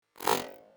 sith-clash.wav — the dark side hit. Fires on task failure and tool errors.